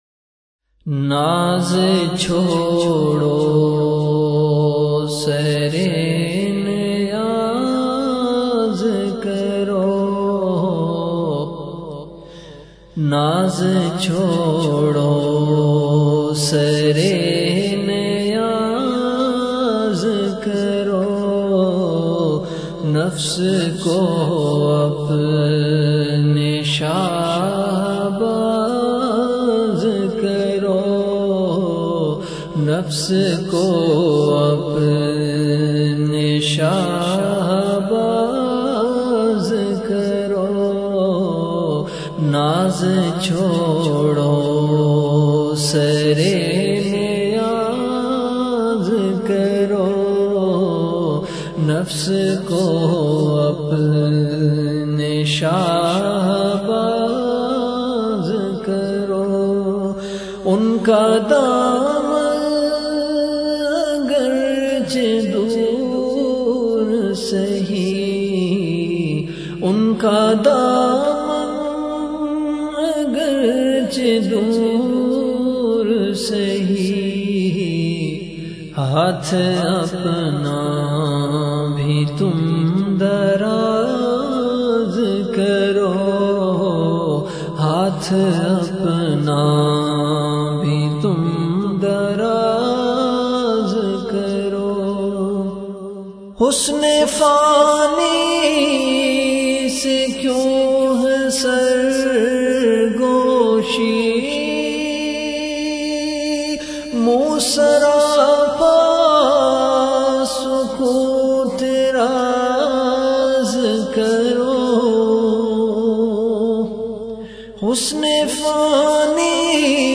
CategoryAshaar
Event / TimeAfter Isha Prayer